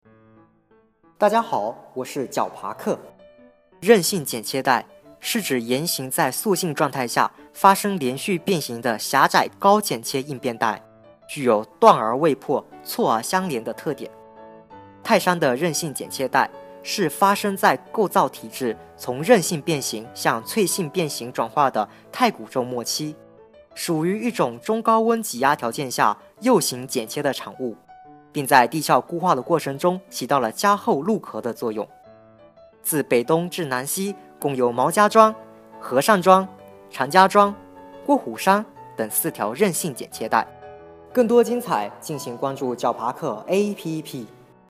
韧性剪切带----- 石破天惊 解说词: 泰山的韧性剪切带，是发生在构造体制从韧性变形向脆性变形转化的太古宙末期，属于一种中高温挤压条件下右行剪切的产物，并在地壳固化过程中起到了加厚陆壳的作用。